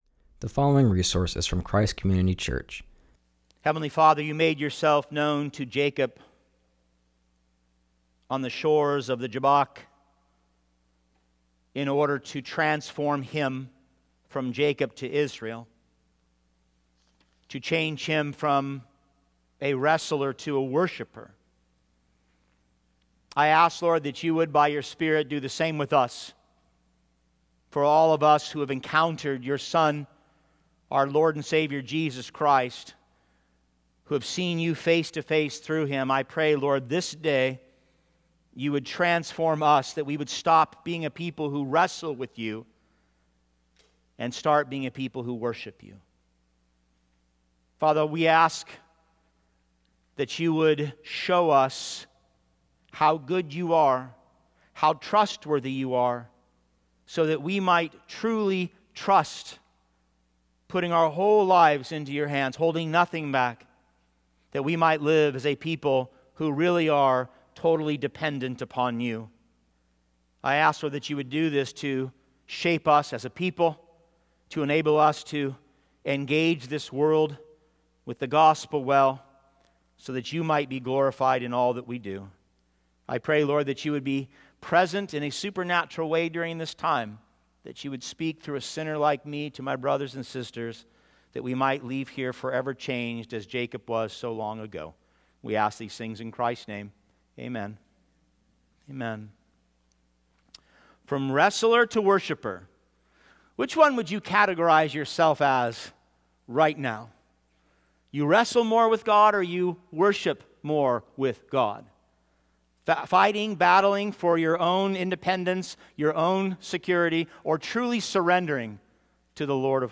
preaches from Genesis 32:1-33